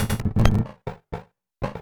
Звуки помех, глитча
Glitch Outage